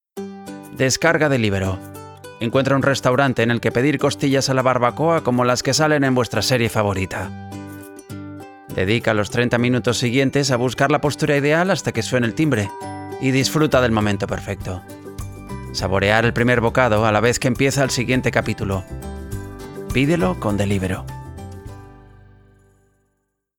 Excellent quality, Speed, Versatile, Young voice - Middle age Excelente calidad, Rapidez, Versátil, Voz joven - Media edad
Sprechprobe: Werbung (Muttersprache):
Large diaphragm microphones for voice, Dynaudio near-field listening, Sound Devices preamp, Beyerdynamic headphones, Mackie Onyx table, and acoustically conditioned room